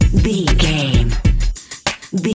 Aeolian/Minor
synthesiser
drum machine
hip hop
Funk
neo soul
acid jazz
energetic
bouncy
Triumphant
funky